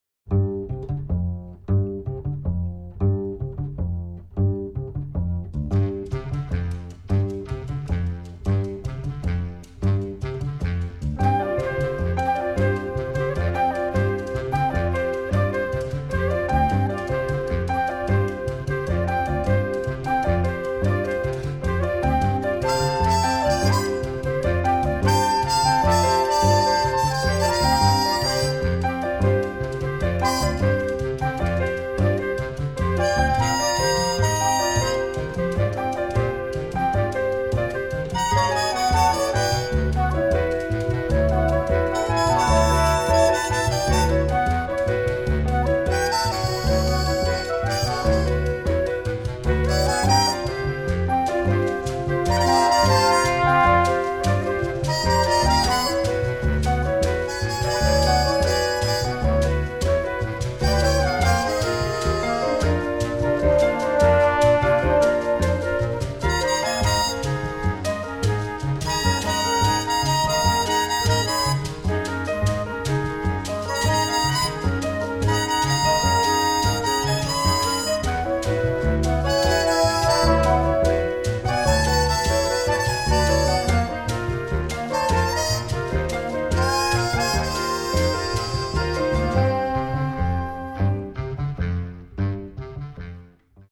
Doubles: flute, clarinet, bass clarinet, soprano
Solos: soprano sax